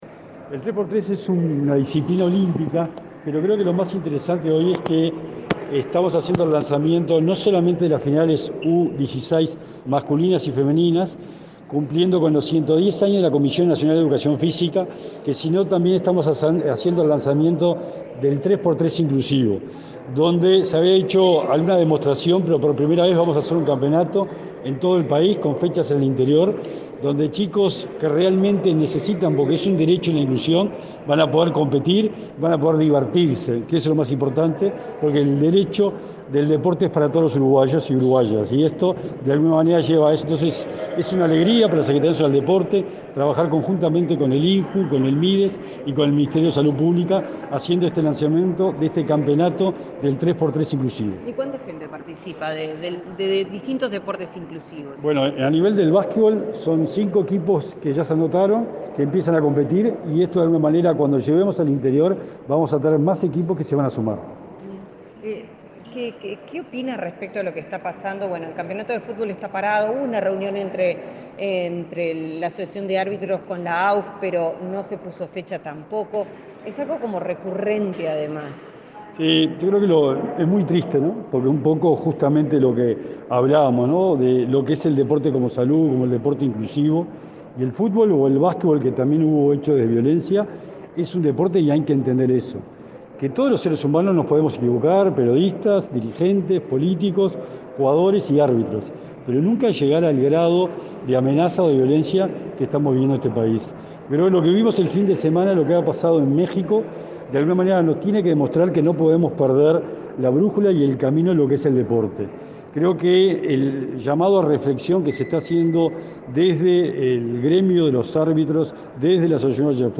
Declaraciones a la prensa del secretario del Deporte